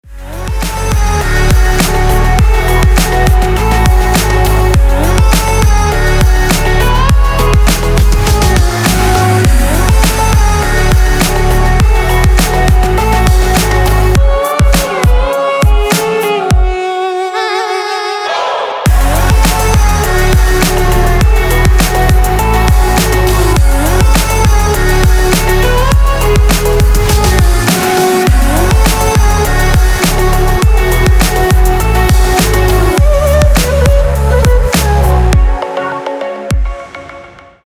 • Качество: 224, Stereo
Electronic
без слов
Melodic